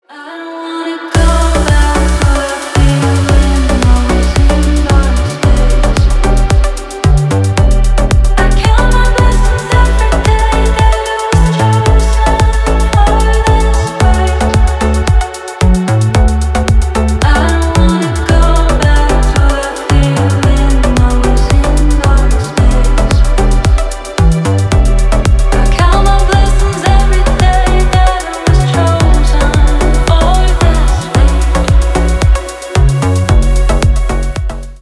Танцевальные
клубные # грустные